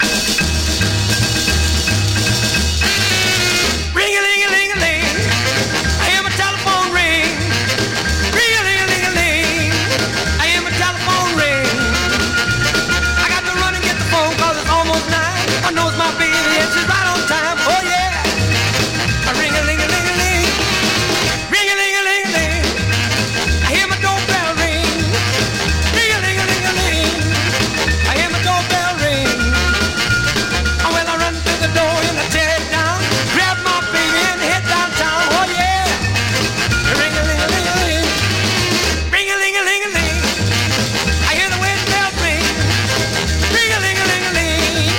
Rhythm & Blues, Rock 'N' Roll　UK　12inchレコード　33rpm　Stereo